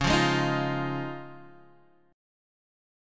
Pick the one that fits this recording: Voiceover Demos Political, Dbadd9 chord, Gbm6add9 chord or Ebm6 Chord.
Dbadd9 chord